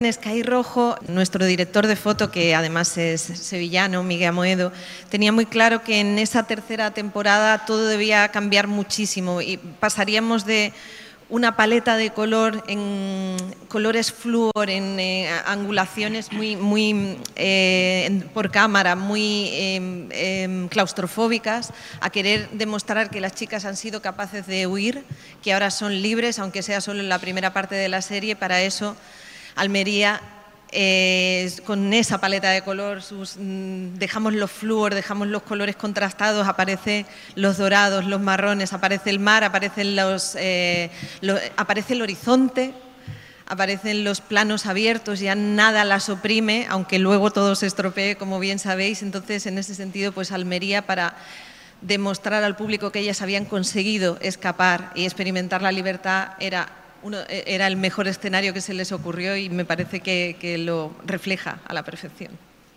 Así se ha mostrado Verónica Sánchez esta mañana en el encuentro con los medios de comunicación en el patio de luces de Diputación, al descubrir su Estrella en el Paseo de la Fama de la ciudad, y seguro que sucederá igual esta noche, cuando el XXIII Festival Internacional de Cine de Almería le entregue el Premio ‘Almería, Tierra de Cine’.